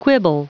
Prononciation du mot quibble en anglais (fichier audio)
Prononciation du mot : quibble